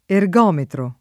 ergometro [ er g0 metro ]